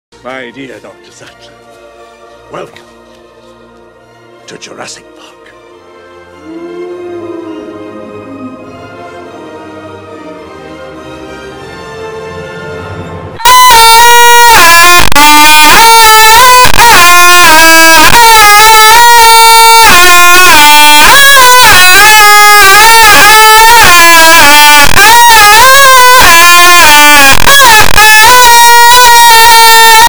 here's the ogg if you want to break your headset/speakers:
I didn't mean to make it loud enough to break a headset.